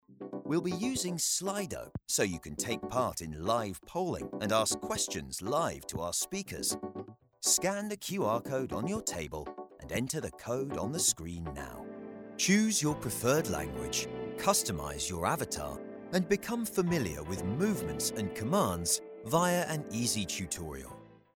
Anglais (Britannique)
Commerciale, Profonde, Polyvalente, Chaude, Corporative
E-learning